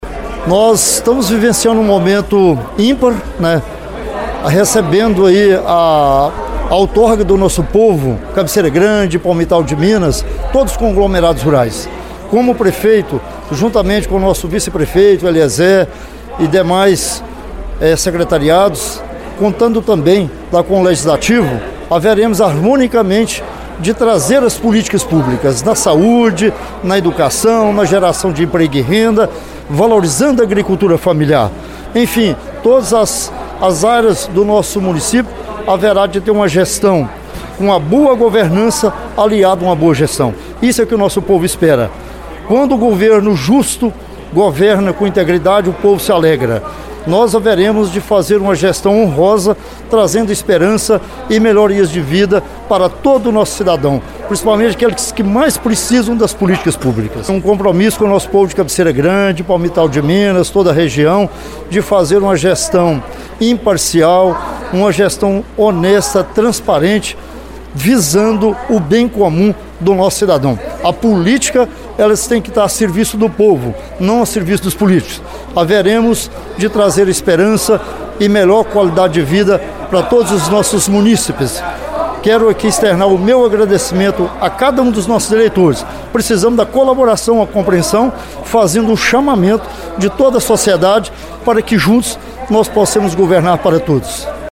A reportagem da Rádio Veredas ouviu alguns dos personagens deste evento que consolidou a democracia nos dois municípios.